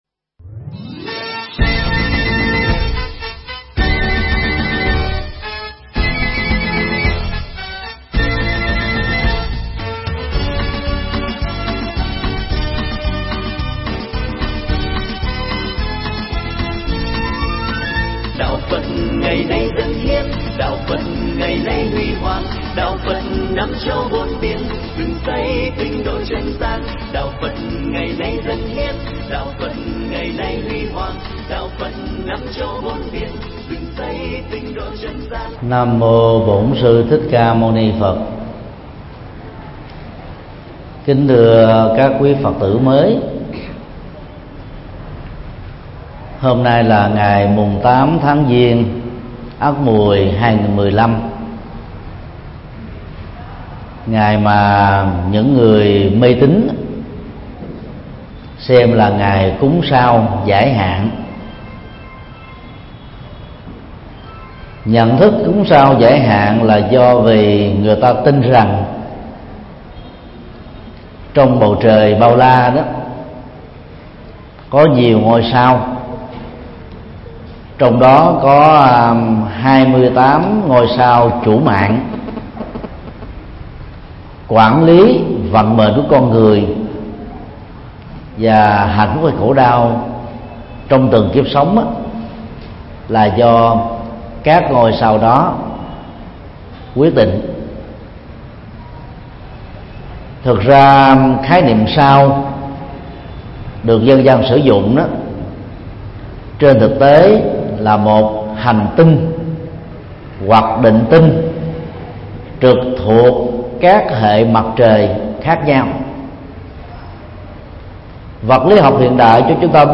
Mp3 Thuyết Pháp Bảy Thực Tập Căn Bản Của Người Phật Tử tại Gia
Giảng nhân buổi lễ Quy Y Tam Bảo tại chùa Giác Ngộ